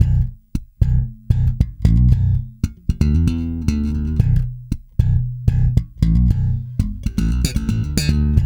-JP THUMB B.wav